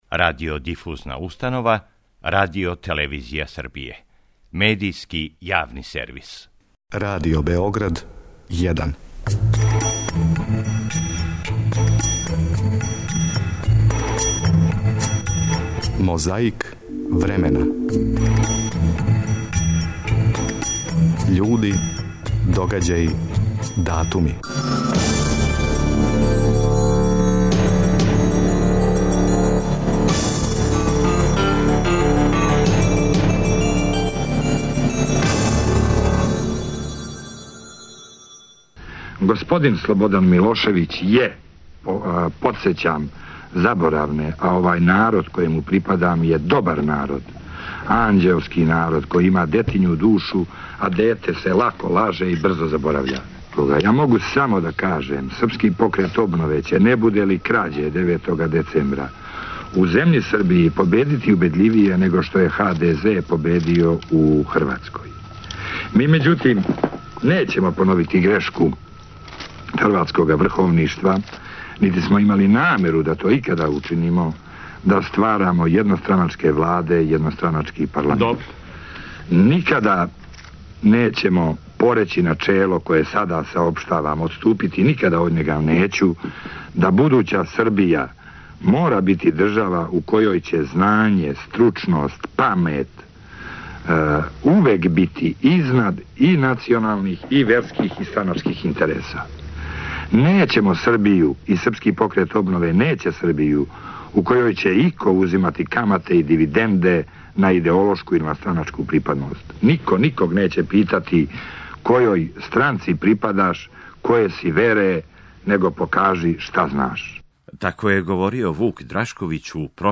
У студију 1 Радио Београда, у прошлом веку - тачније - 3. новембра 1990. године, гостовао је Вук Драшковић.
Подсећа на прошлост (културну, историјску, политичку, спортску и сваку другу) уз помоћ материјала из Тонског архива, Документације и библиотеке Радио Београда.